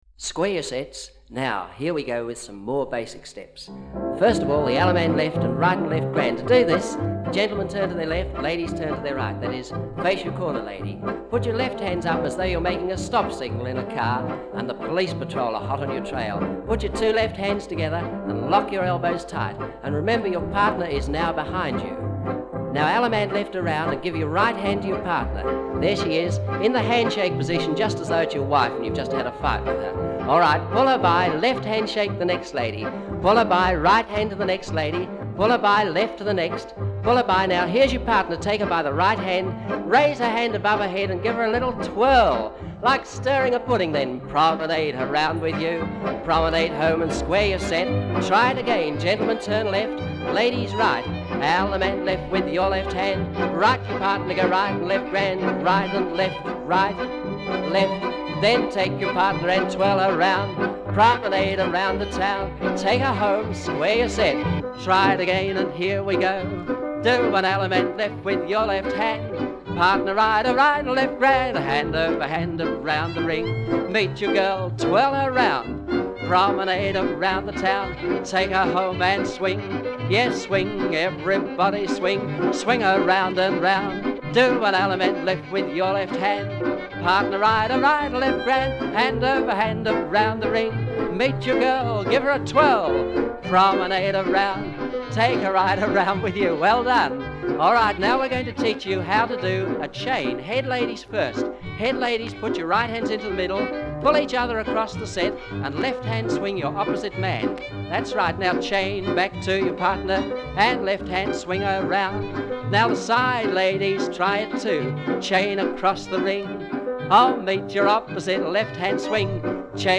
#2 Teaching Track -